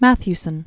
Math·ew·son
(măthy-sən), Christopher Known as "Christy." 1880-1925.